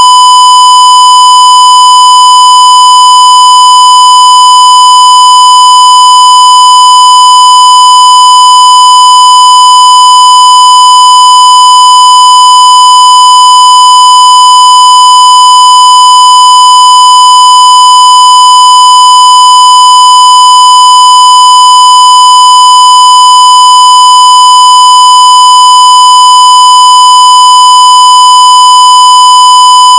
1KHZ_SQUARE.wav